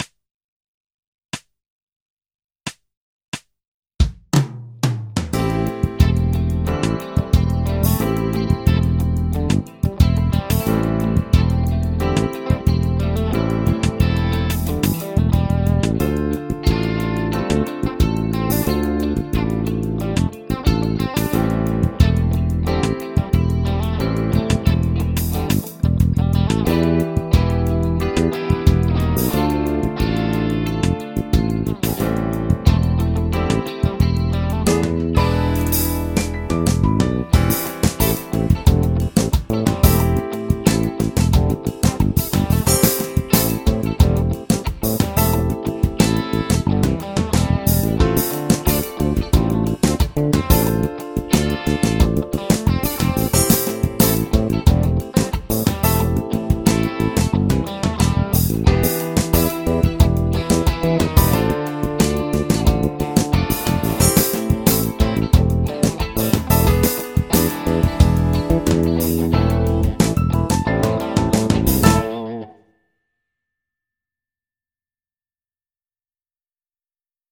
ブルース・スケール ギタースケールハンドブック -島村楽器